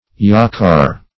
Yacare \Yac"a*re`\ (y[a^]k"[.a]*r[=a]`), n. [See Jacare.]